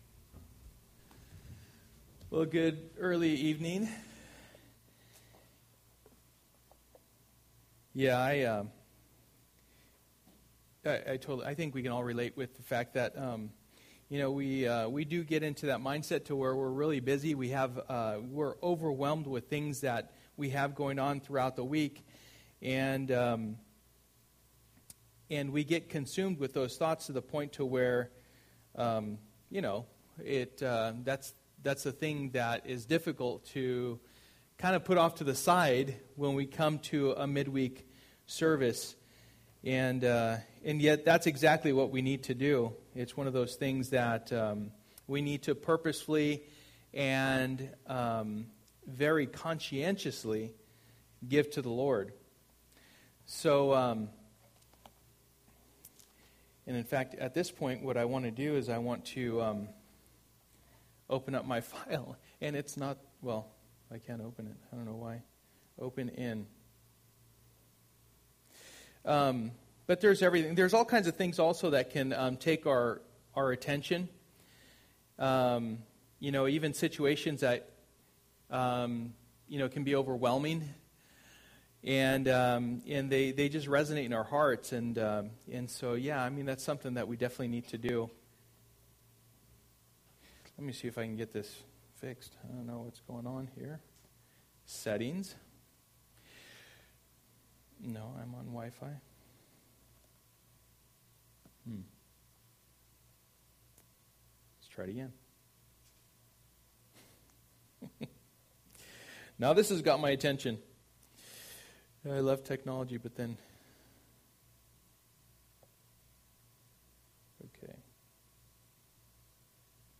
Through the Bible Passage: Exodus 25:10-40 Service: Wednesday Night %todo_render% « God Makes All Things New The Eternal City of Glory!